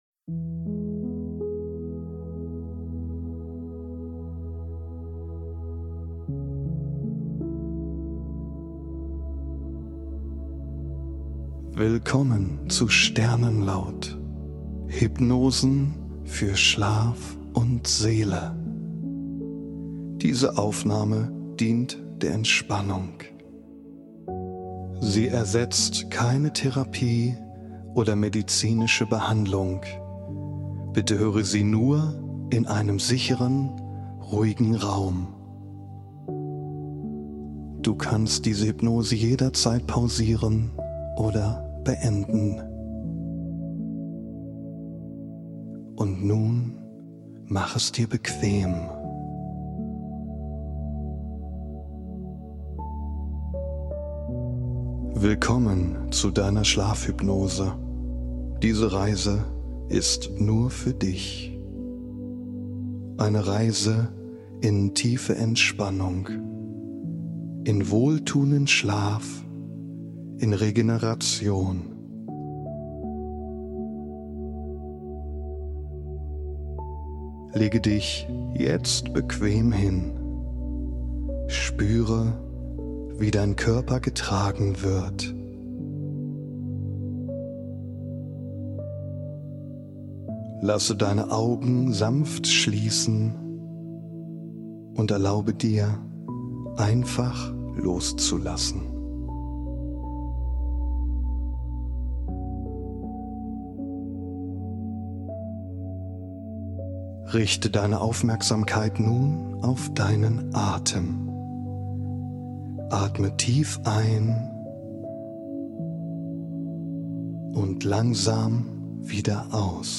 In dieser Episode begleite ich dich mit einer beruhigenden Schlafhypnose, gesprochen mit sanfter Stimme und unterlegt mit zarter, entspannender Musik.
Sternenlaut-Schlafhypnose_b.mp3